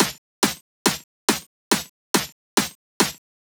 Вложения pitchedsnare.wav pitchedsnare.wav 886,7 KB · Просмотры: 164